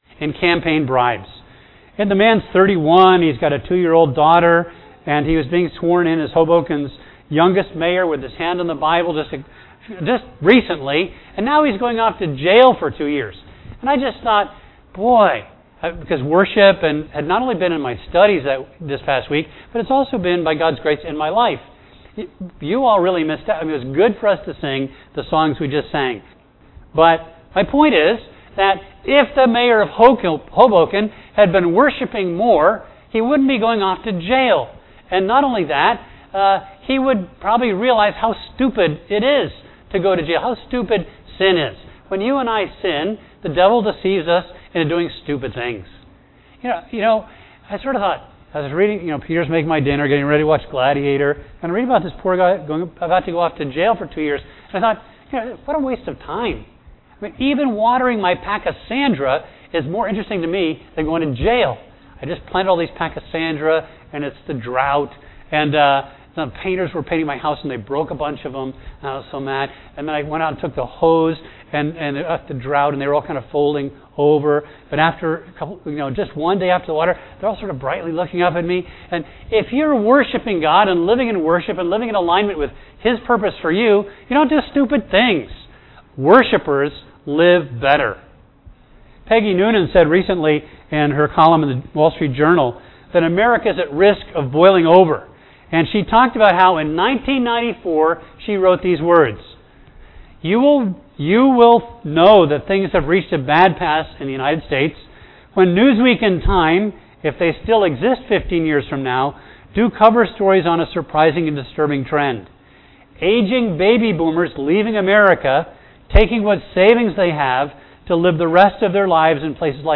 A message from the series "David."